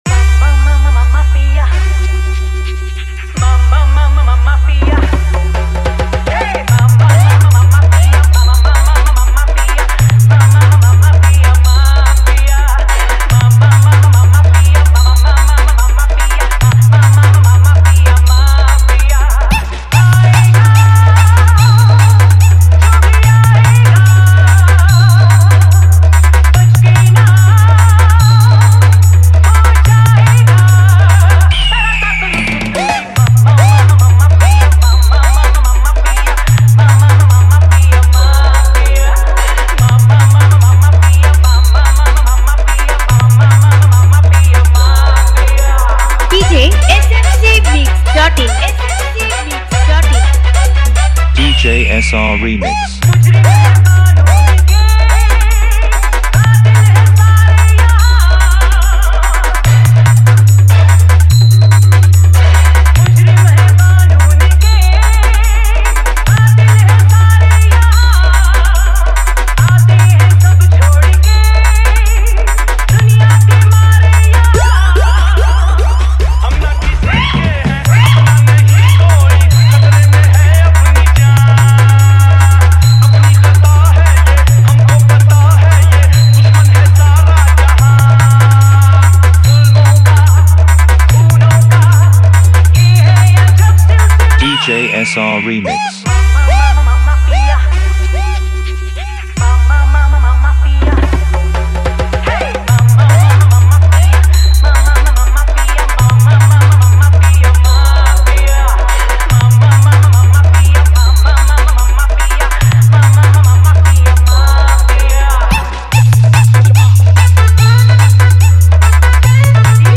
পুজোর সেরা ডিজে বাংলা গানের Pop Bass Humming মিক্স